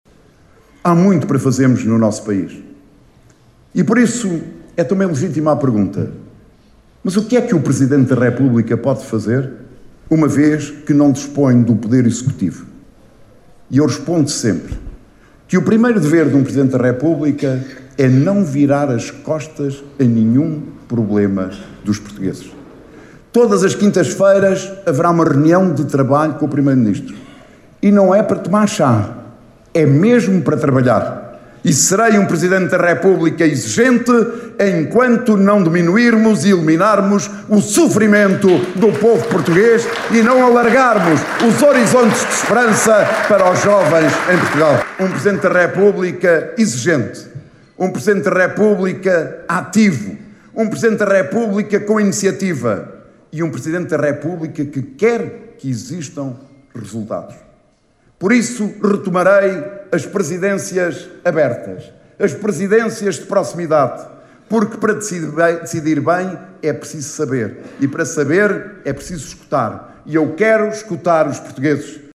António José Seguro esteve esta segunda-feira, na Vidigueira, onde participou num almoço convívio, no Mercado Municipal.